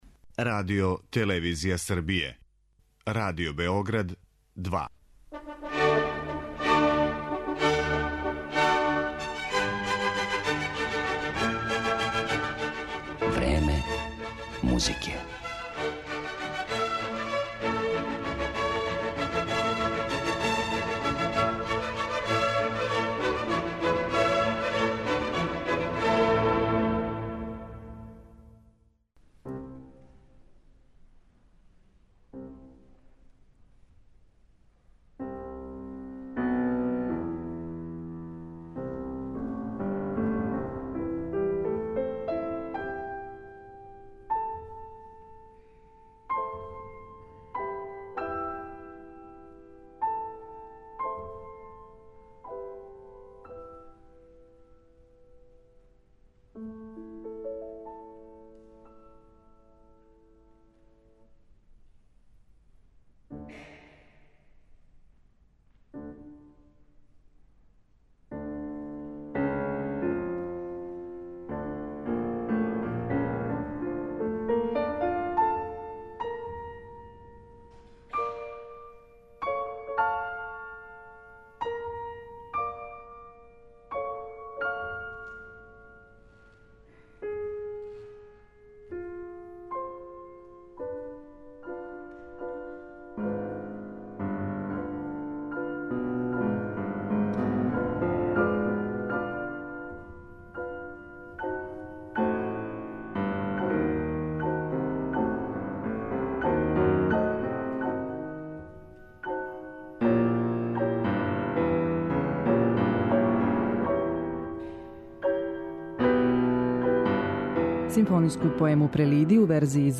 композиције за два клавира и клавир четвороучно